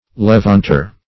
Levanter \Le*vant"er\ (l[-e]*v[a^]nt"[~e]r), n. [From Levant,